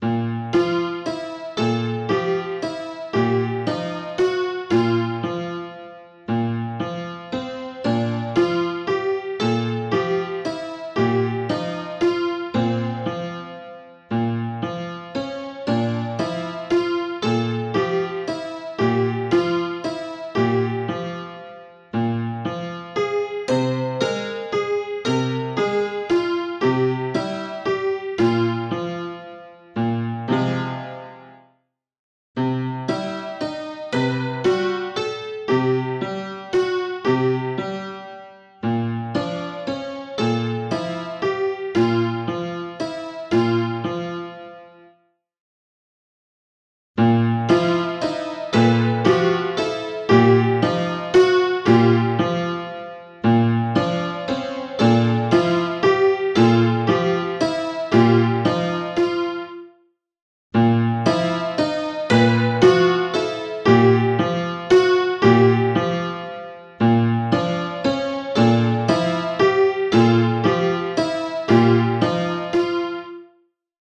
first section: unnamed waltz second section: man.ogg third section: both of them combined fourth section: both combined, slight alterations (moving notes above or below 1-2 semitones)
this version also has unnamed waltz sped up to the speed of man.ogg so that they sound more similar